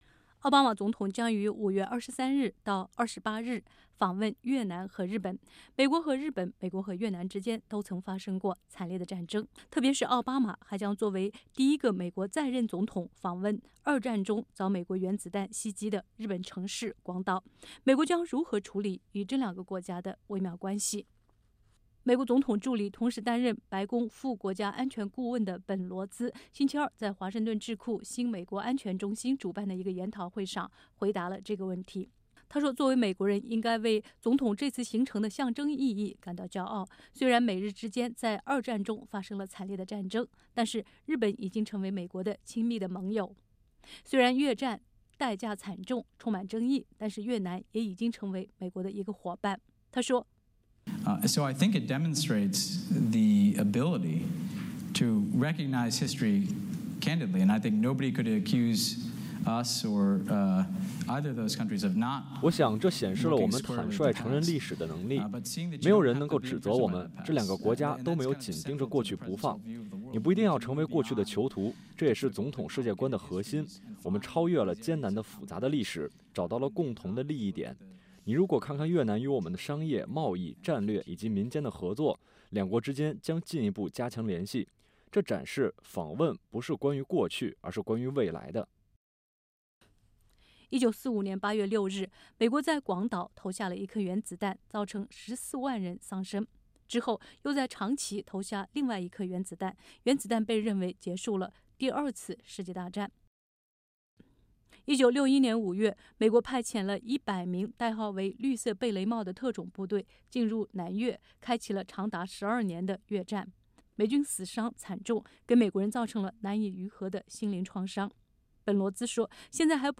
美国总统助理，同时担任白宫副国家安全顾问本·罗兹（Ben Rhodes）星期二在华盛顿智库新美国安全中心（ Center for a New American Security）主办的一个讨论会上回答了这个问题。